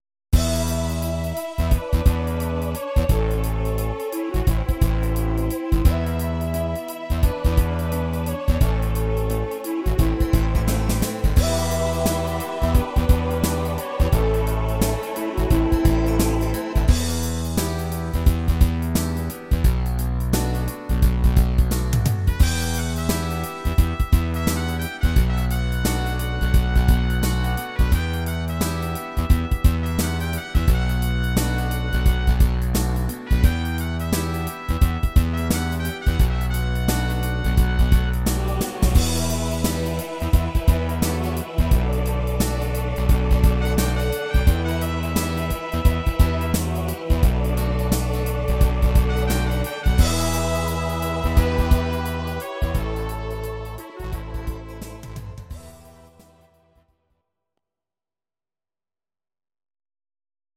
These are MP3 versions of our MIDI file catalogue.
Please note: no vocals and no karaoke included.
Your-Mix: Medleys (1041)